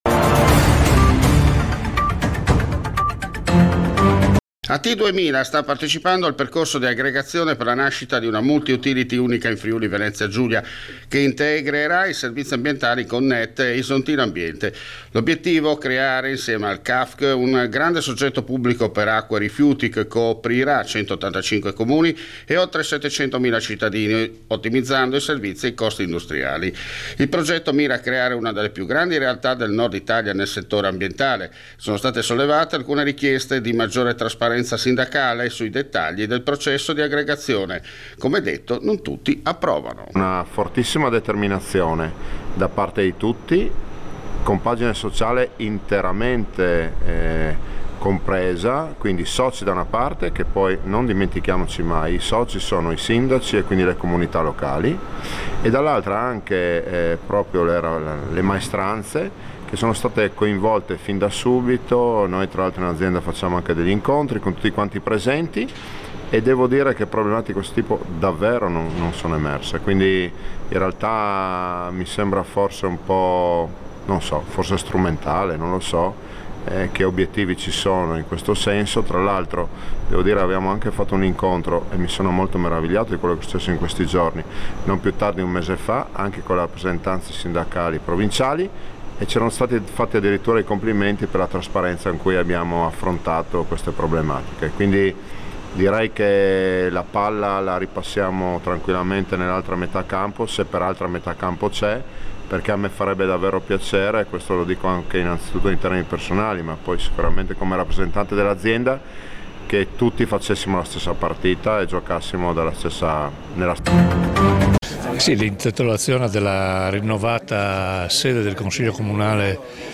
La sala consiliare del Comune di Buja intitolata alla memoria di Giuseppe Zamberletti “il papà della ricostruzione del Friuli” post terremoto. Intervista a Riccardo Riccardi Assessore alla Protezione Civile Fvg
In ricordo di Enzo Cainero. Incontro a Gemona del Friuli con l’intervista all’Assessore regionale alle Finanze Barbara Zilli.